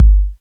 808K_A.wav